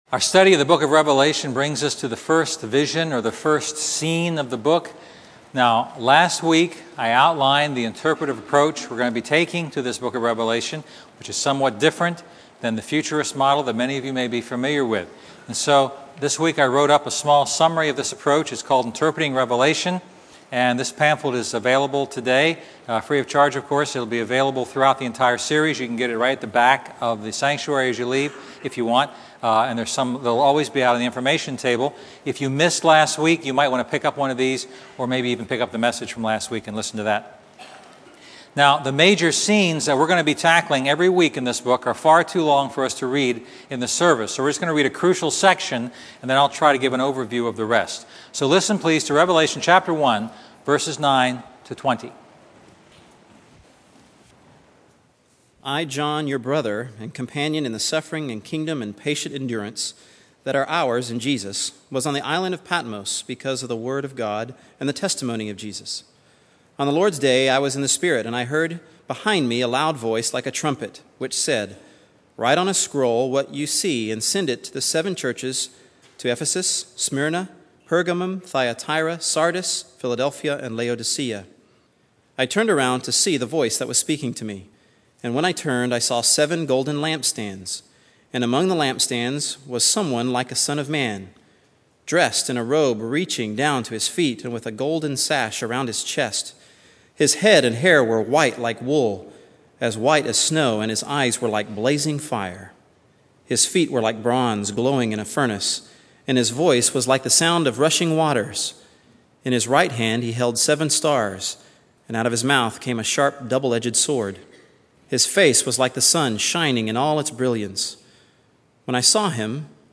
Expository